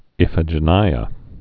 (ĭfə-jə-nīə, -nēə)